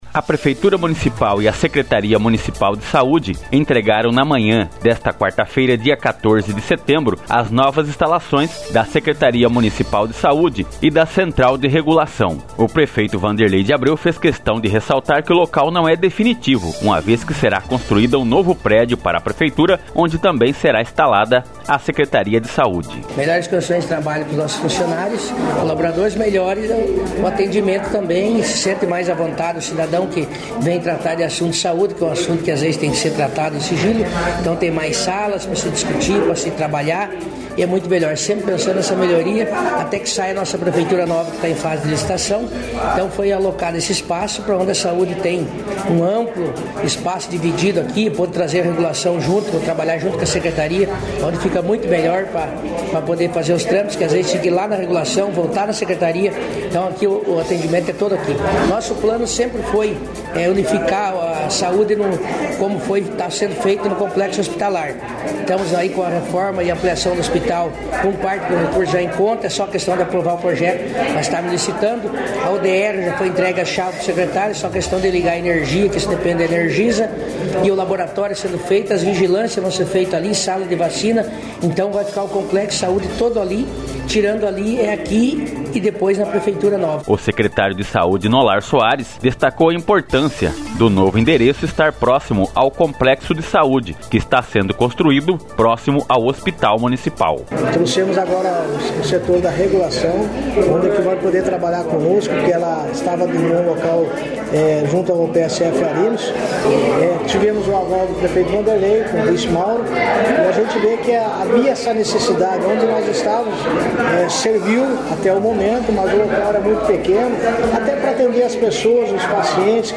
O prefeito Vanderlei de Abreu, o vice-prefeito Mauro Ferreira e o secretário de Saúde de Porto dos Gaúchos Nolar Soares, apresentaram as novas instalações da secretaria municipal de Saúde e da Central de Regulação na manhã desta quarta-feira (14/09).
reportagem-da-inaguracao-secretaria.mp3